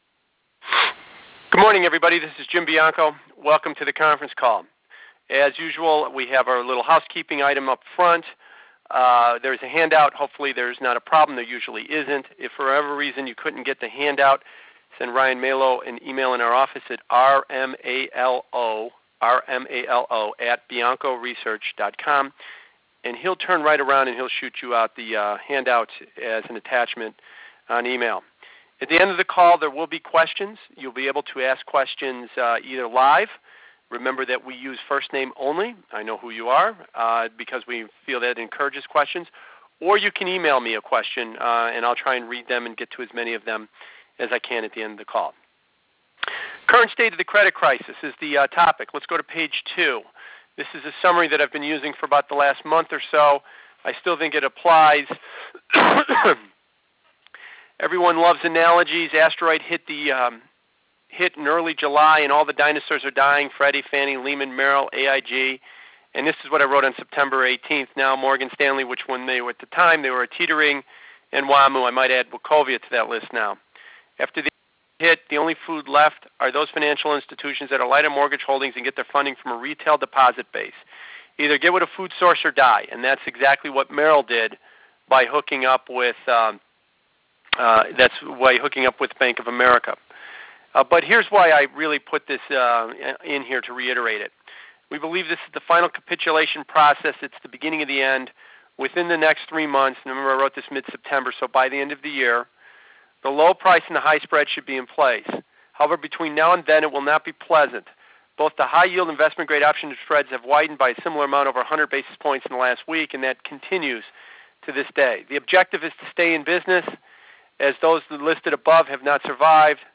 Yesterday we had a conference call and discussed this in great deal.